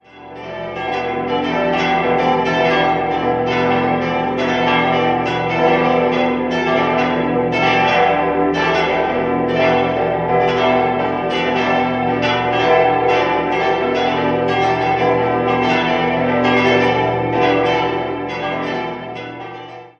Idealsextett: h°-d'-e'-g'-a'-h' Die zweitgrößte Glocke stammt von der Gießerei Spannagl und wurde 1878 gegossen.